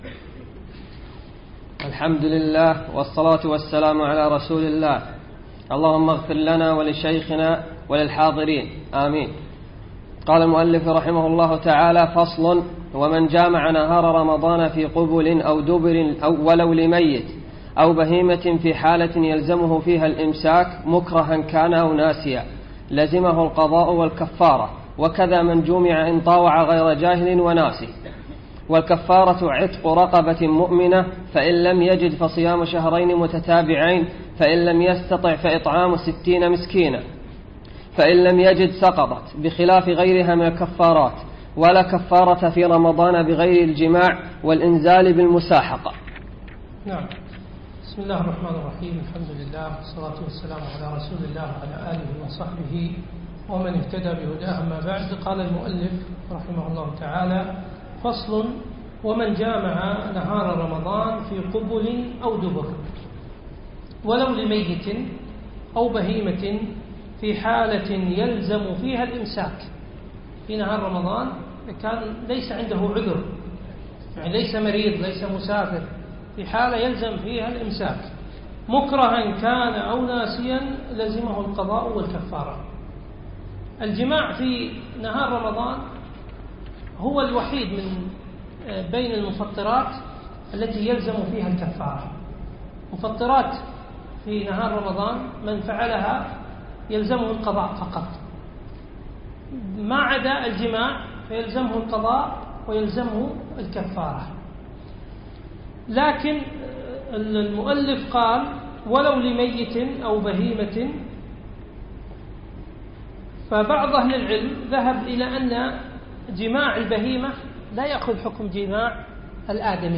الدرس الثاني